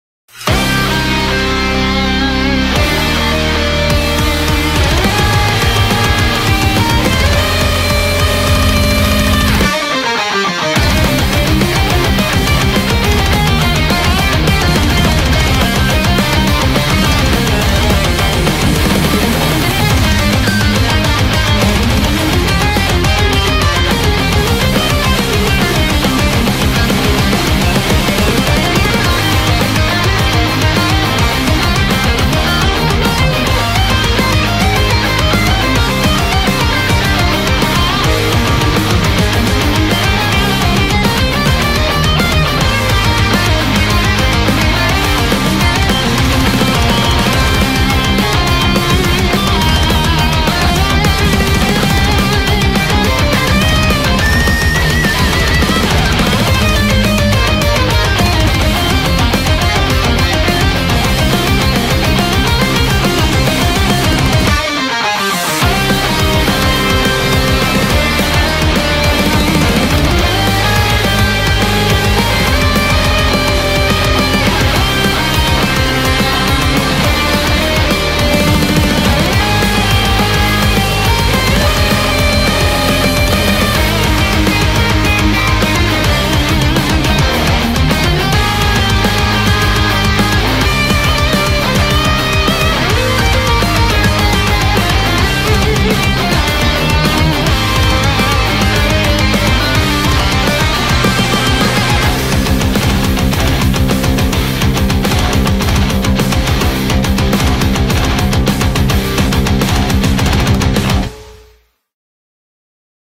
BPM210
Audio QualityPerfect (Low Quality)